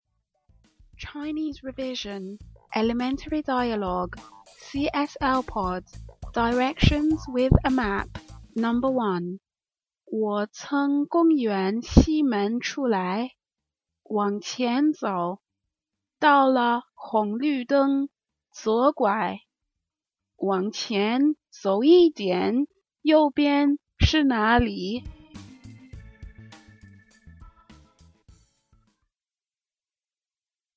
Today there are 2 dialogues (kind of) that will introduce some vocabulary words that crop up when asking for directions. If you get these down you will have a much better time finding your way around China or any other Chinese Mandarin speaking country/place.